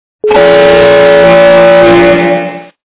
» Звуки » Авто, мото » Гудок - корабля
При прослушивании Гудок - корабля качество понижено и присутствуют гудки.
Звук Гудок - корабля